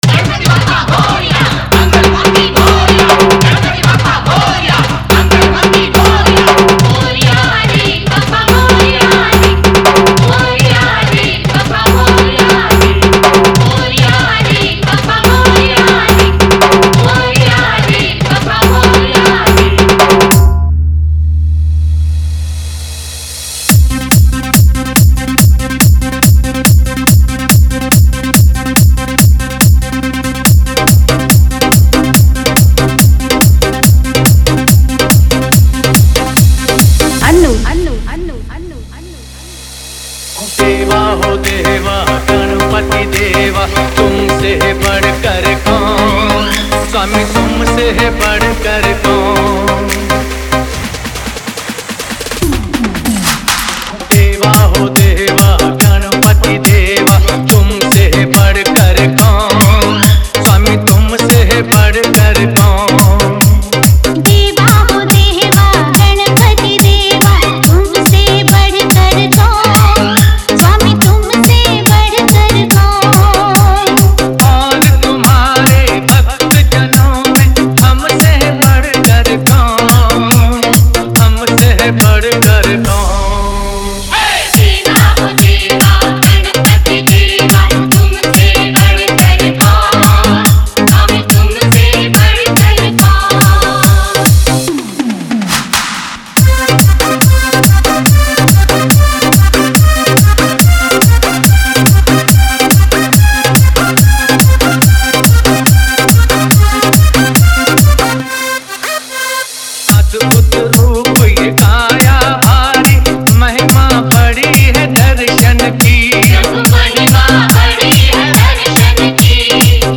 Ganesh Chaturthi Dj Remix Song